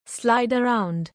slide-around.mp3